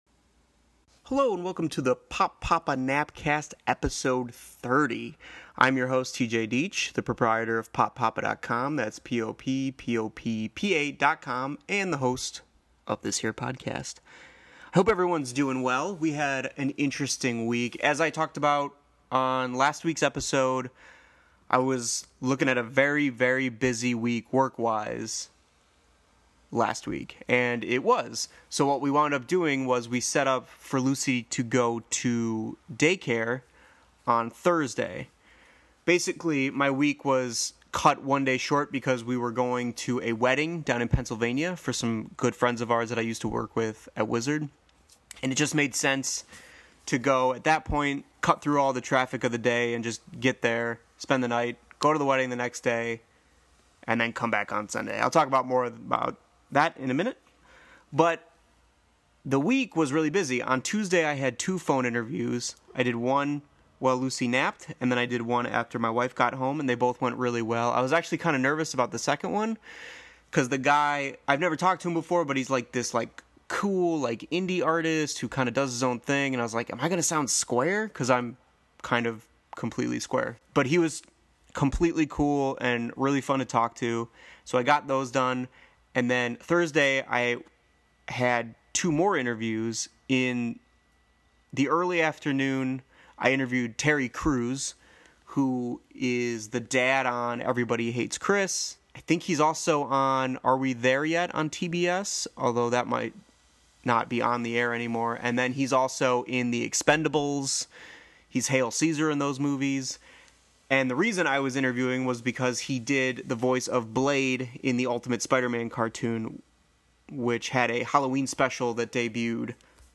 The Pop Poppa Nap Cast Episode 30 features talk of business, a fun wedding excursion packed with friends and some praise for a DVD player holder and film. The last 10 minutes or so of the episode sound static- y, but it’s actually the rain. Sorry about that!